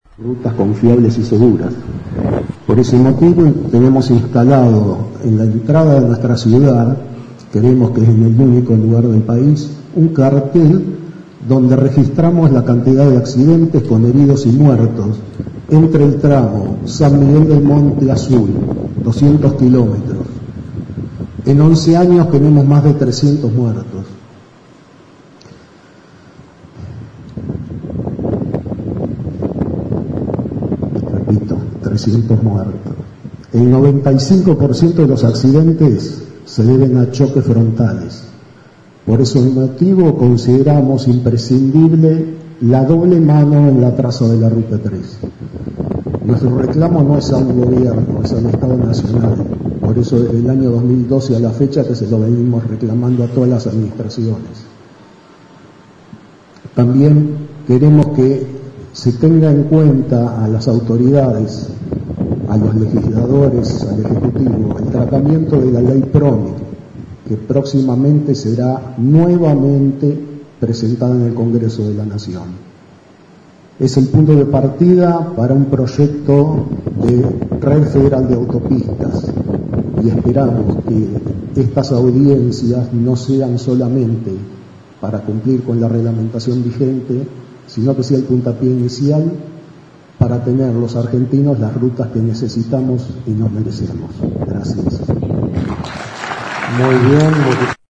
(incluye audios) Ayer miércoles en el Centro Cultural de la ciudad San Miguel del Monte se llevó a cabo la audiencia pública Zona Sur.